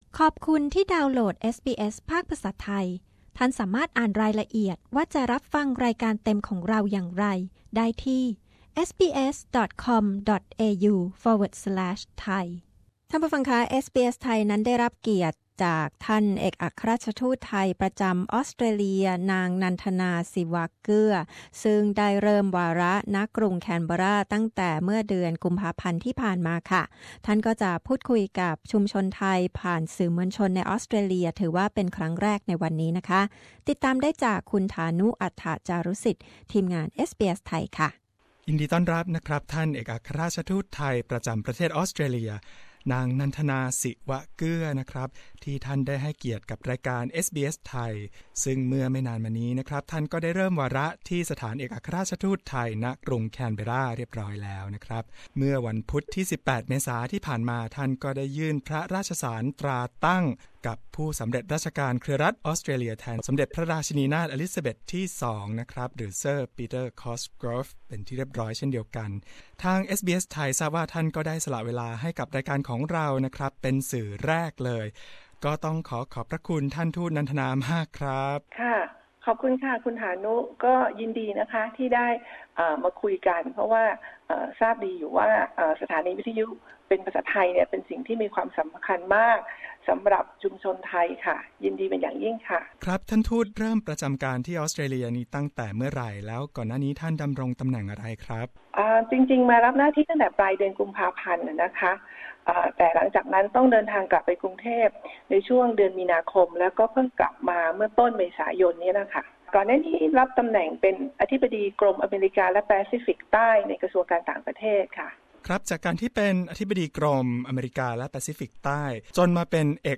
เอสบีเอสไทย ได้รับเกียรติในการแนะนำท่านเอกอัครราชทูตไทยประจำประเทศออสเตรเลียท่านใหม่ต่อชุมชนชาวไทย ท่านได้พูดคุยกับเราเกี่ยวกับการเริ่มวาระ ณ กรุงแคนเบร์รา ในปี พ.ศ. 2561 นี้